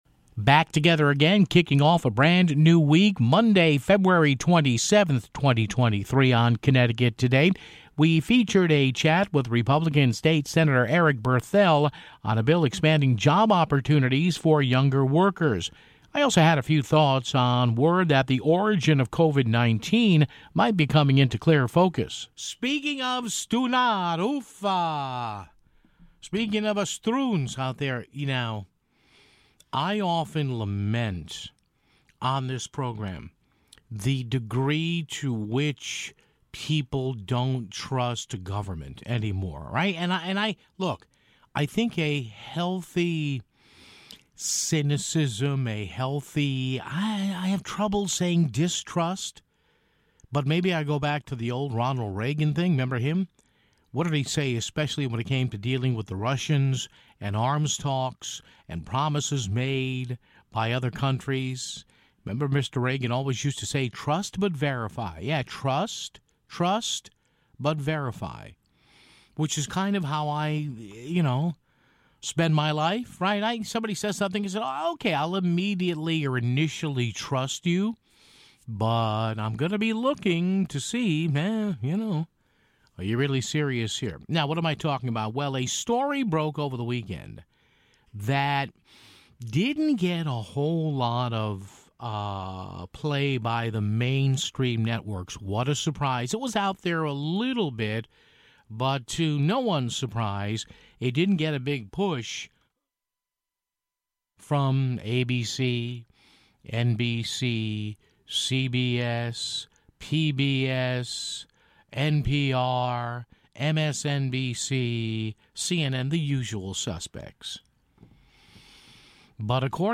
featured a chat with GOP State Sen. Eric Berthel on a bill expanding job opportunities for younger workers (0:22).